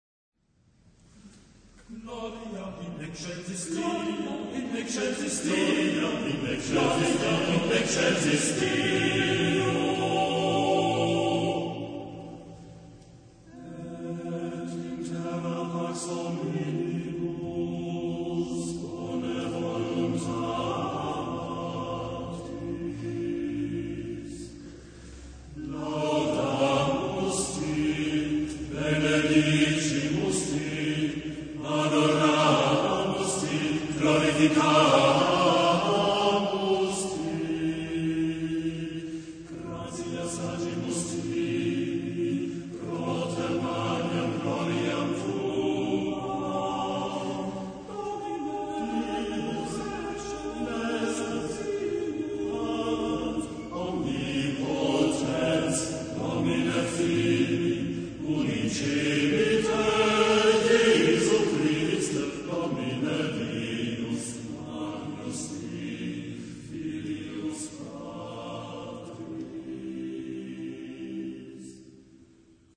Genre-Stil-Form: Messe ; geistlich
Chorgattung: TTTBBB  (6 Männerchor Stimmen )
Tonart(en): frei